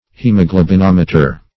Search Result for " hemoglobinometer" : The Collaborative International Dictionary of English v.0.48: Hemoglobinometer \Hem`o*glo"bin*om"e*ter\, n. (Physiol.
hemoglobinometer.mp3